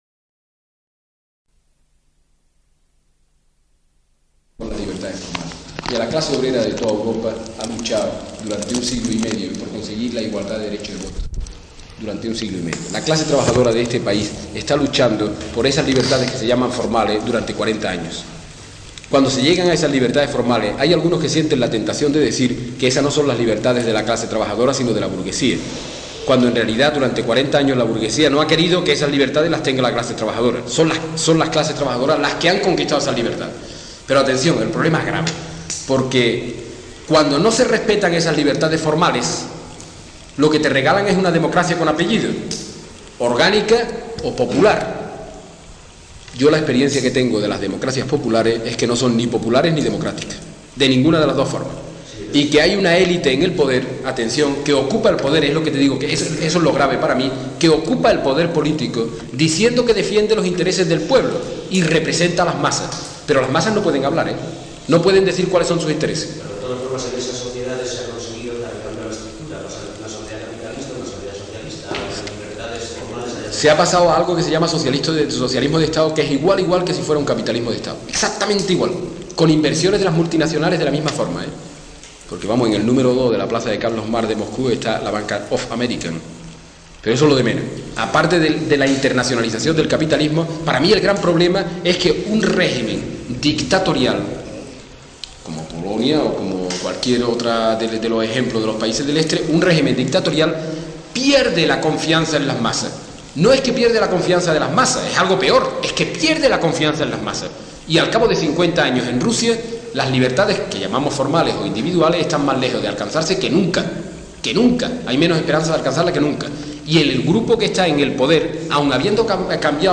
Grabación sonora que recoge un coloquio celebrado en Orense sobre socialismo.
[Inicio abrupto].